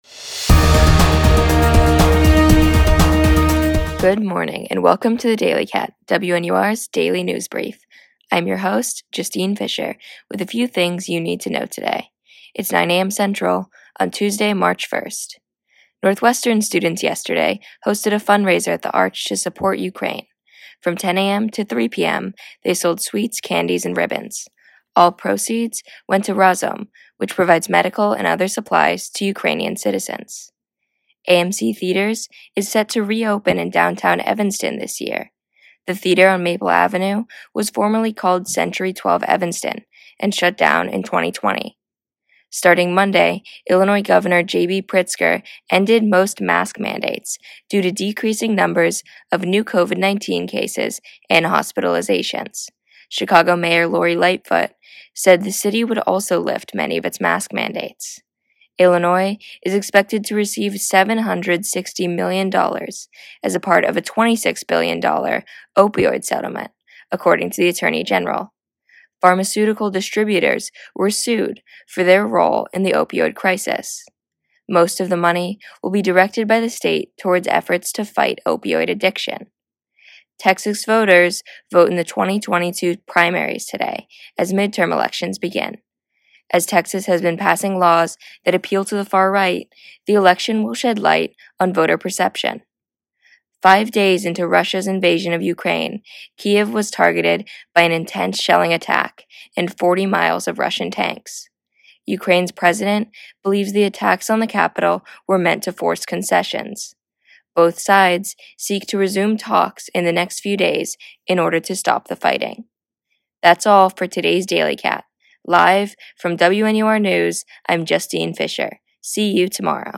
WNUR News’ Daily Briefing – March 1st, 2022 Northwestern students support Ukraine, AMC Theaters set to reopen, Illinois ending most mask mandates, Illinois receiving Opioid settlement, Texas primaries, attacks on Kyiv WNUR News broadcasts live at 6 pm CST on Mondays, Wednesdays, and Fridays on WNUR 89.3 FM